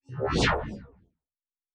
pgs/Assets/Audio/Sci-Fi Sounds/Movement/Synth Whoosh 5_4.wav at master
Synth Whoosh 5_4.wav